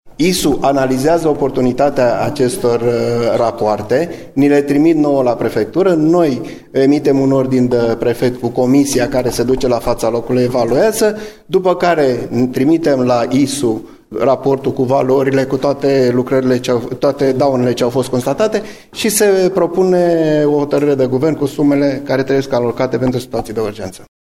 Primăriile trimit aceste rapoarte la ISU şi la SEGA iar traseul ulterior este explicat de subprefectul Adrian Folea: